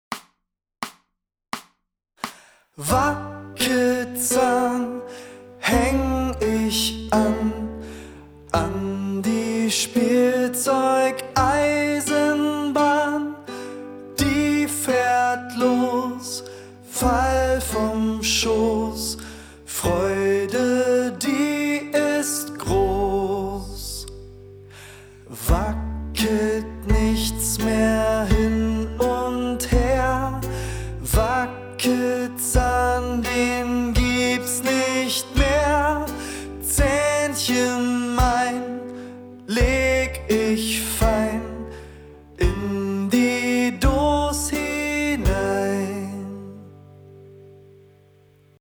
langsam